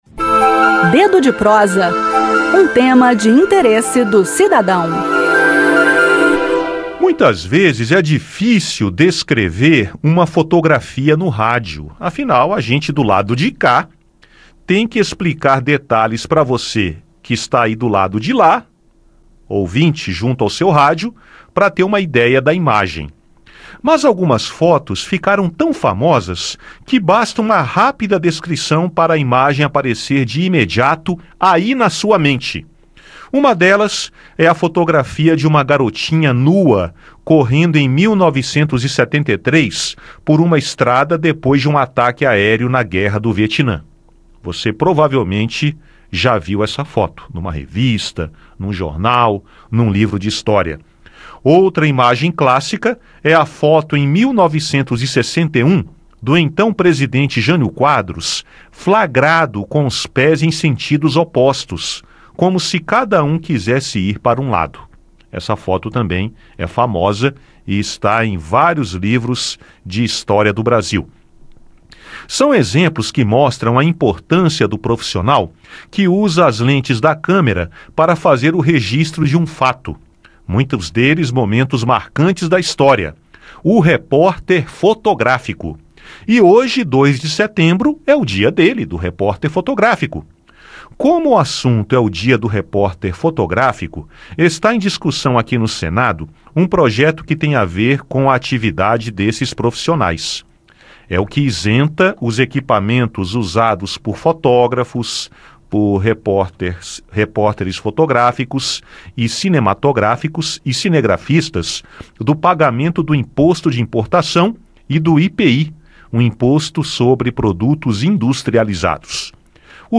bate-papo